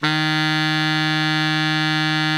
BARI  FF D#2.wav